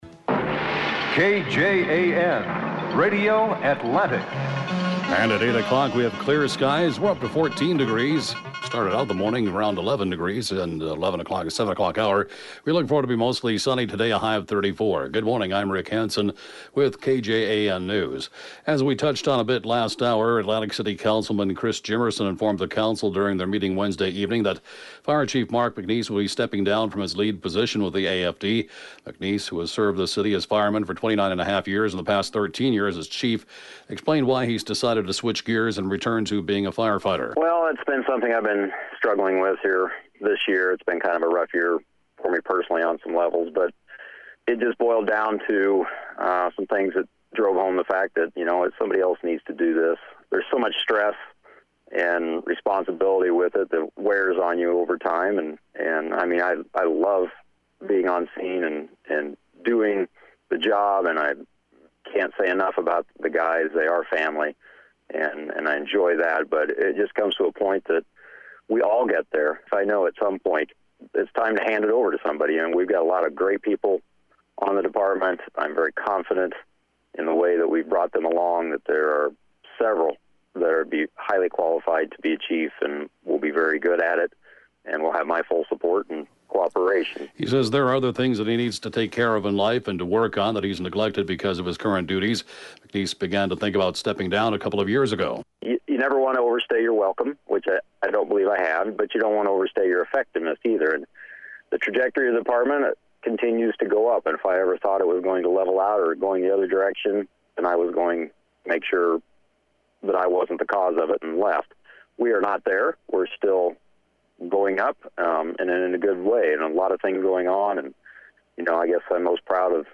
(Podcast) KJAN 8-a.m. News, 11/7/19